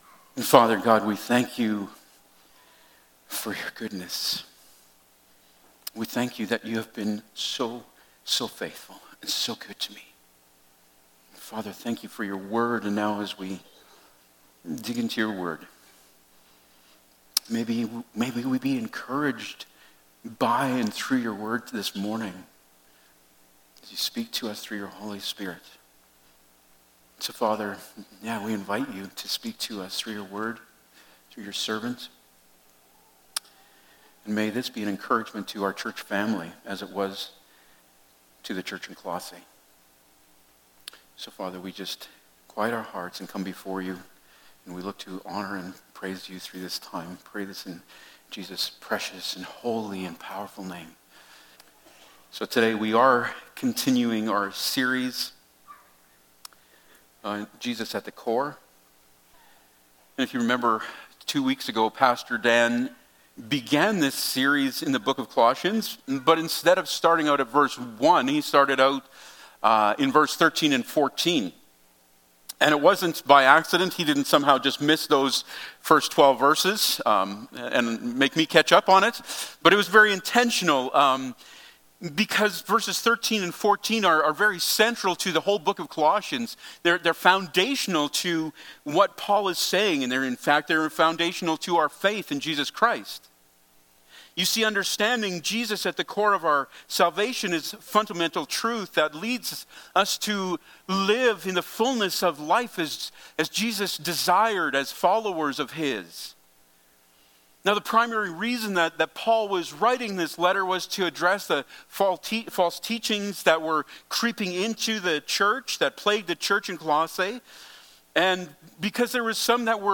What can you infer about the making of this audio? Passage: Col. 1:1-14 Service Type: Sunday Morning